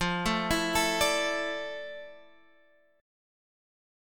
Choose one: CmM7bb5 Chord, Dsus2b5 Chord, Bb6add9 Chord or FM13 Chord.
FM13 Chord